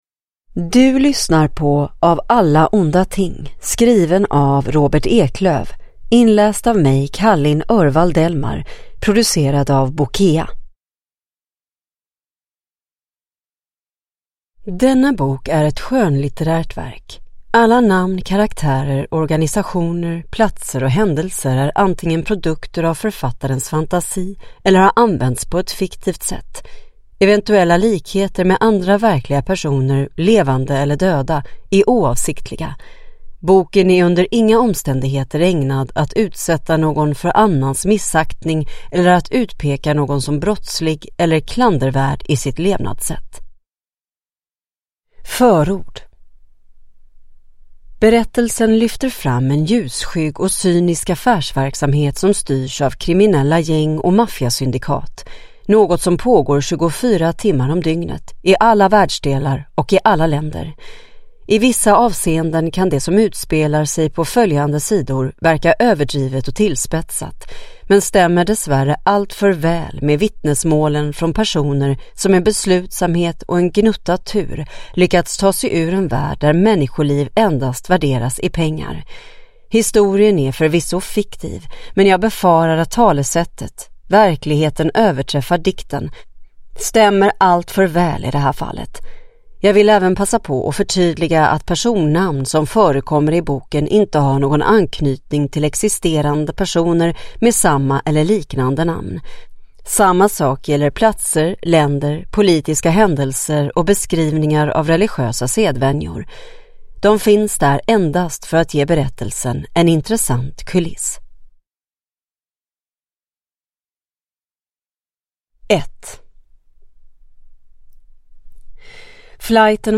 Av alla onda ting – Ljudbok